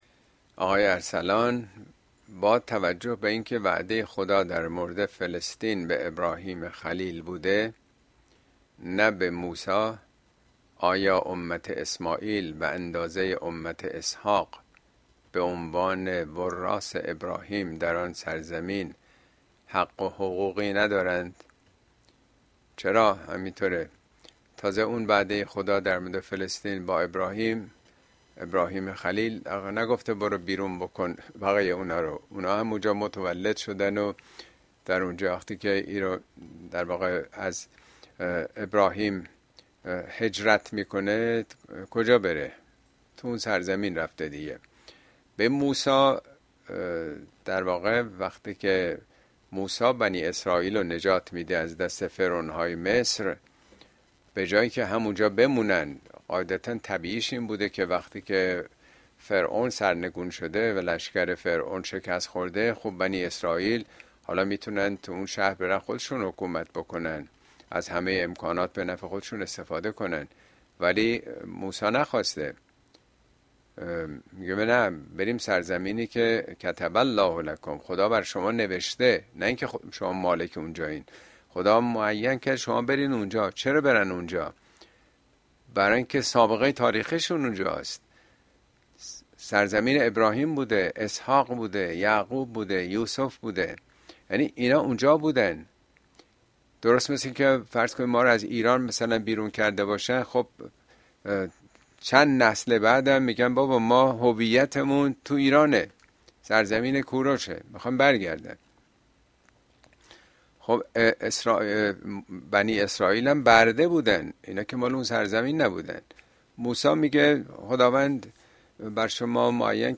` موضوعات اجتماعى اسلامى طغیان کبیر اين سخنرانى به تاريخ ۱۹ فوریه ۲۰۲۵ در كلاس آنلاين پخش شده است توصيه ميشود براىاستماع سخنرانى از گزينه STREAM استفاده كنيد.